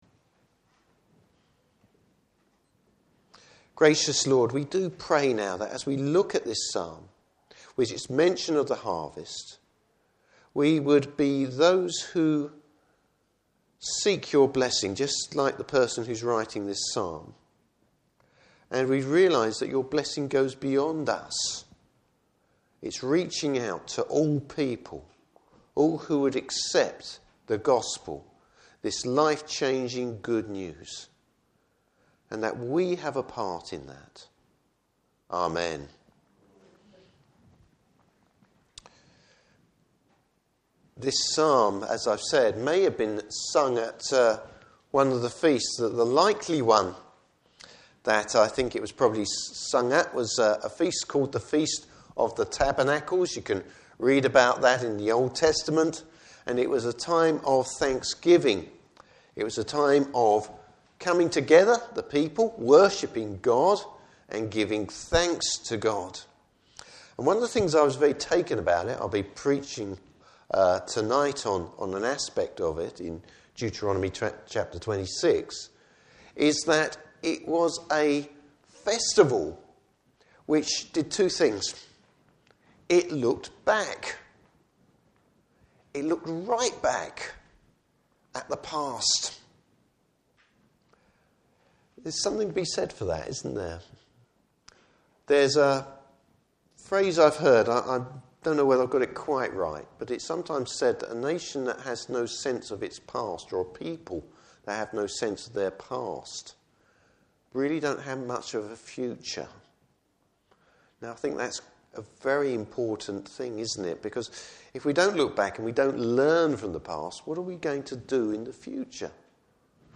Service Type: Morning Service Bible Text: Psalm 67.